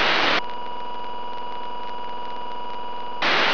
Сигналик на анализ №3